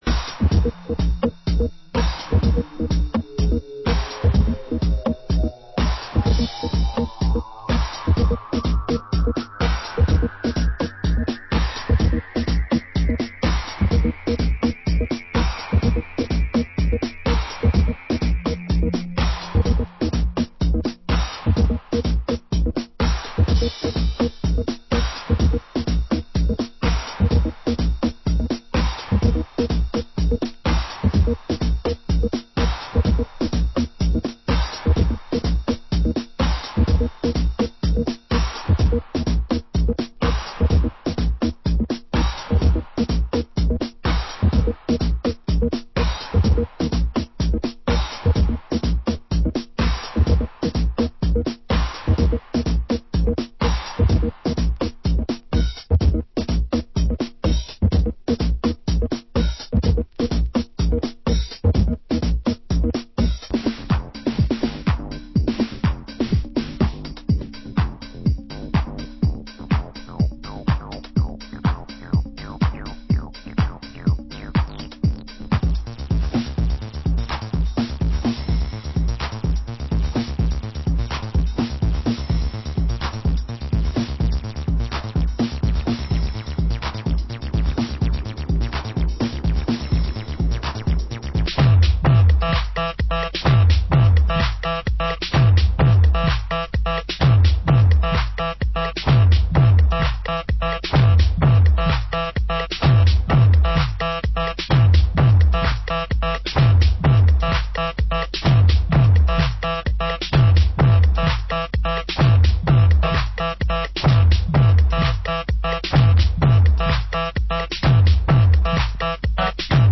Genre: Chicago House